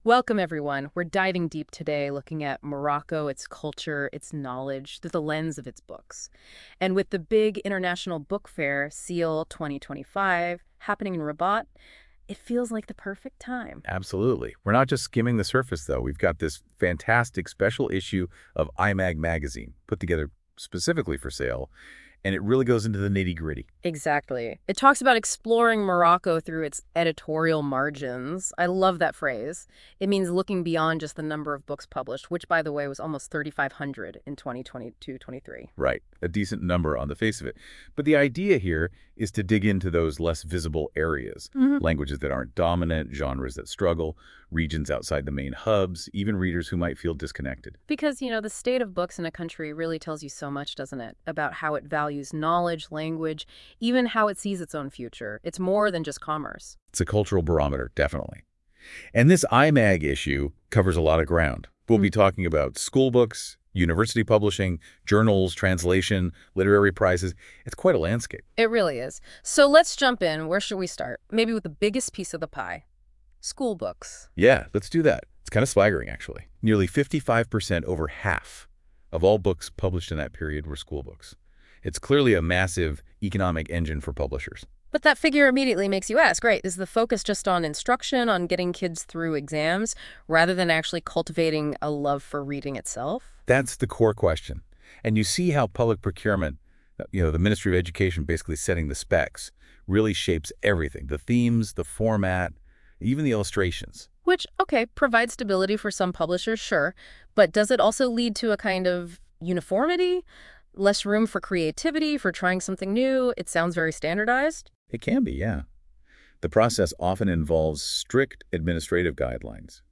Les chroniqueurs de la Web Radio R212 ont lus attentivement l'hebdomadaire économique de L'ODJ Média et ils en ont débattu dans ce podcast I-MAG-Spécial-SIEL-2025.mp3 (15.3 Mo) Quel est l'état général de l'édition et de la lecture au Maroc, et pourquoi, malgré une production croissante, la lecture reste-t-elle un défi ?